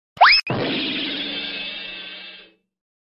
Meu Efeito Sonoro De Desmaiando sound effects free download